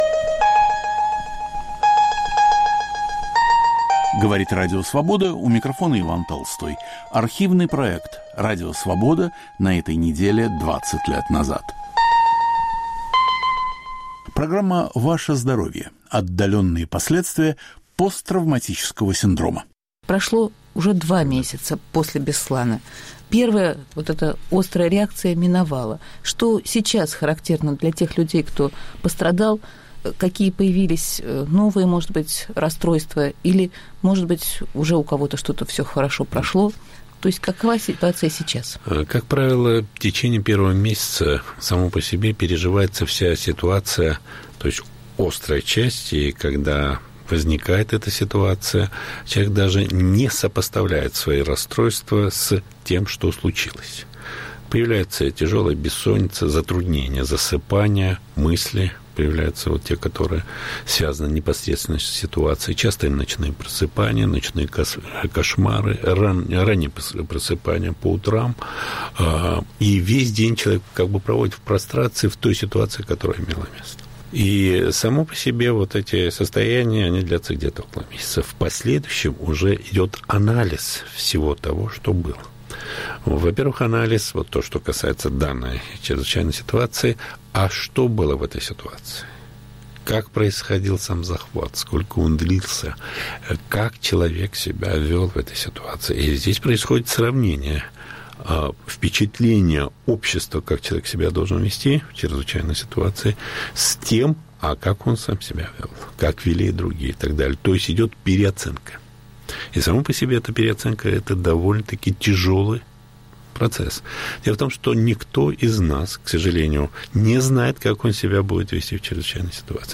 Последствия теракта в Беслане. Разговор